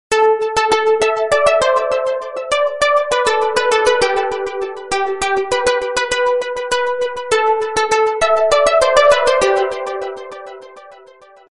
Guitar sms tone ringtone free download
Message Tones